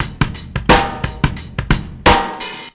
Amsterhall 87bpm.wav